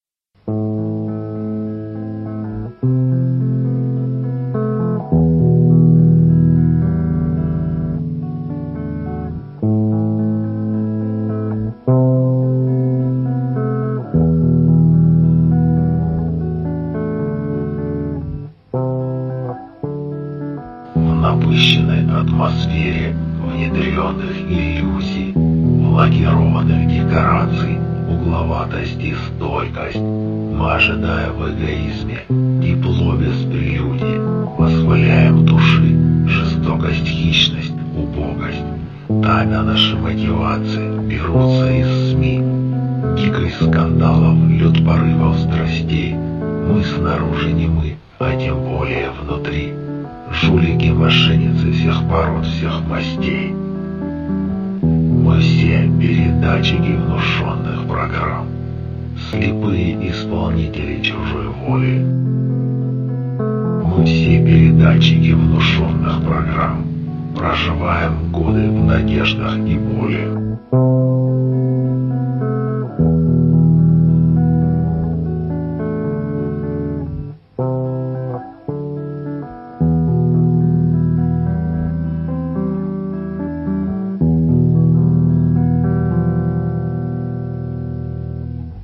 Рок опера В атмосфере внедренных иллюзий
Ром баллад